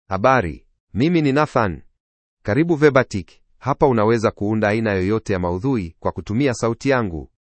MaleSwahili (Kenya)
NathanMale Swahili AI voice
Voice sample
Male
Nathan delivers clear pronunciation with authentic Kenya Swahili intonation, making your content sound professionally produced.